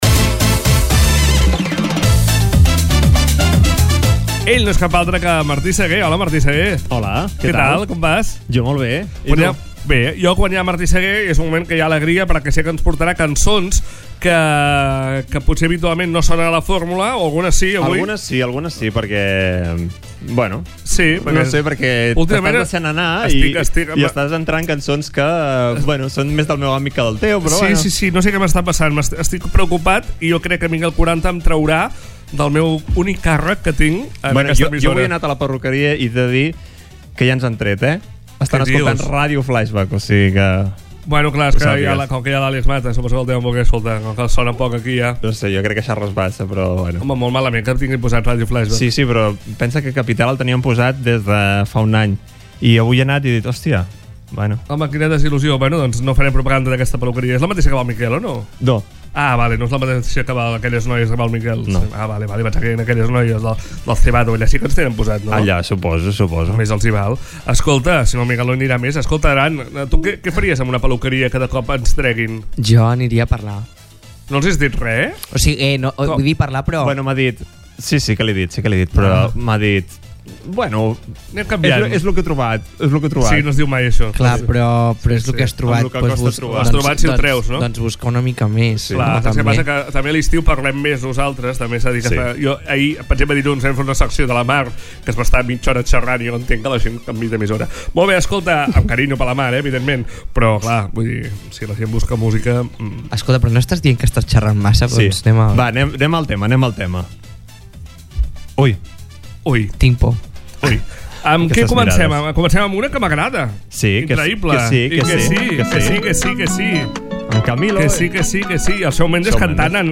el so més urbà de la setmana